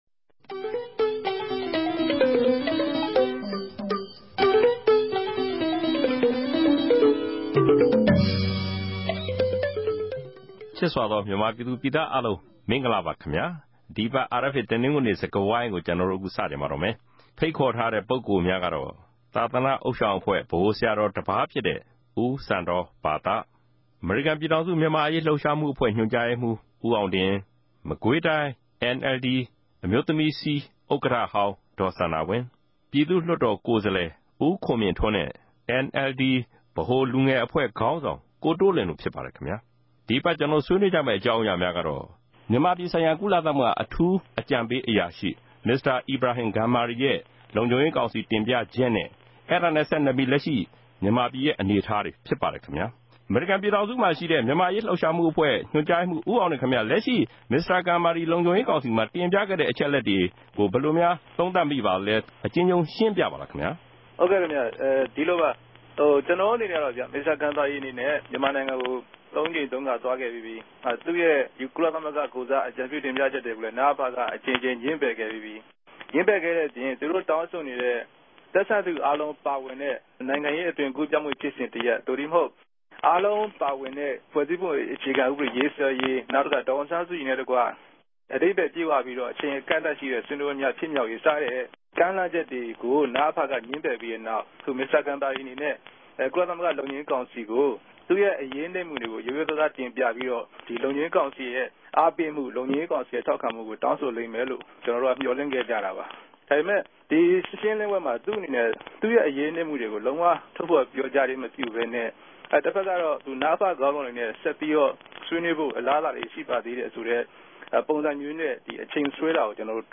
အခုတပတ်အာအက်ဖ်အေ တနဂဿေိံစြကားဝိုင်းမြာ ကုလသမဂ္ဂ အတြင်းရေးမြြးခဵြပ်ရဲႚ ူမန်မာိံိုင်ငံဆိုင်ရာ အထူးအုကံပေးအရာရြိ မင်္စတာ အီဗရာဟင် ဂမ်ဘာရီရဲႚ ကုသမဂ္ဂလုံူခံြရေးကောင်စီကို အစီအရင်ခံတင်ူပခဵက်၊ အဲဒၝနဲႚဆကိံြယ်္ဘပီး လက်ရြိူမန်မာူပည်ရဲႚ အနေအထားတေနြဲႚ ပတ်သက်လိုႚ ဆြေးေိံြးထားပၝတယ်။